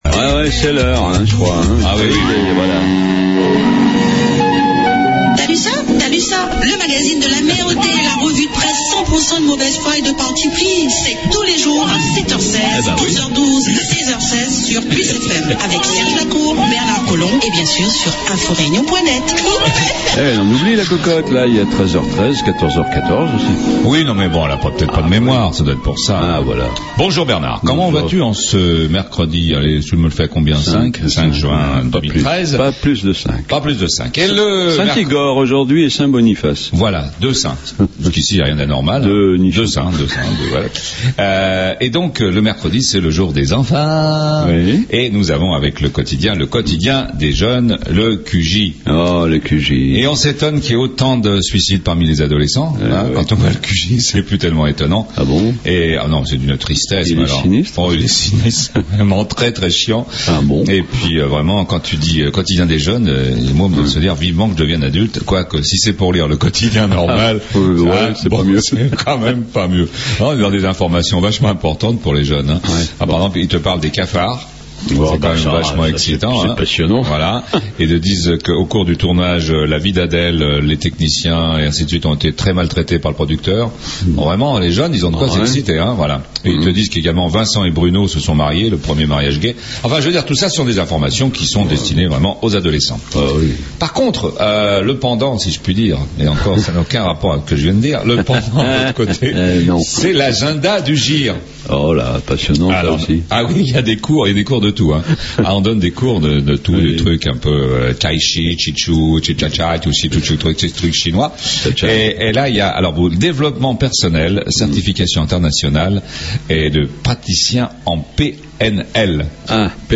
La Revue de Presse politiquement incorrecte la mieux informée, la plus décalée, la plus drôle, la moins sérieuse et la plus écoutée sur PLUS FM 100.6 sur le Nord, et 90.4 dans l'ouest...